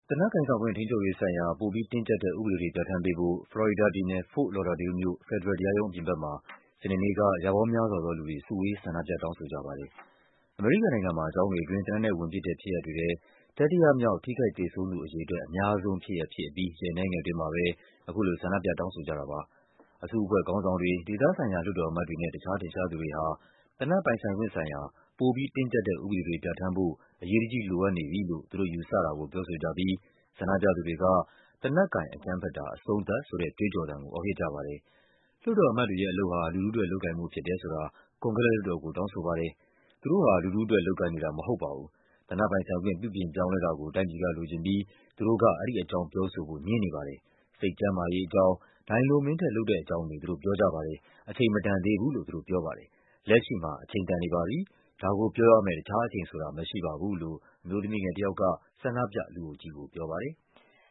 သေနတ်ကိုင်ဆောင်ခွင့် ထိန်းချုပ်ရေးဆိုင်ရာ ပိုပြီးတင်းကျပ်တဲ့ဥပဒေတွေ ပြဌာန်းပေးဖို့ ဖလော်ရီဒါပြည်နယ်၊ Fort Lauderdale မြို့၊ ဖက်ဒရယ်တရားရုံးအပြင်ဘက်မှာ စနေနေ့က ရာပေါင်းများစွာသောလူတွေ စုဝေး ဆန္ဒပြ တောင်းဆိုကြပါတယ်။
အစုအဖွဲ့ခေါင်းဆောင်တွေ၊ ဒေသဆိုင်ရာလွှတ်တော်အမတ်တွေနဲ့ တခြားထင်ရှားသူတွေဟာ သေနတ်ပိုင်ဆိုင်ခွင့်ဆိုင်ရာ ပိုပြီး တင်းကျပ်တဲ့ဥပဒေတွေ ပြဌာန်းဖို့ အရေးတကြီးလိုအပ်နေပြီလို့ သူတို့ယူဆတာကို ပြောဆိုကြပြီး ဆန္ဒပြသူတွေက သေနတ်ကိုင် အကြမ်းဖက်တာ အဆုံးသတ် ဆိုတဲ့ကြွေးကြော်သံကို အော်ဟစ်ကြပါတယ်။